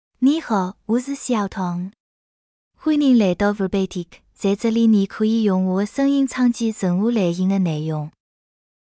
FemaleChinese (Wu, Simplified)
Xiaotong — Female Chinese AI voice
Xiaotong is a female AI voice for Chinese (Wu, Simplified).
Voice sample
Xiaotong delivers clear pronunciation with authentic Wu, Simplified Chinese intonation, making your content sound professionally produced.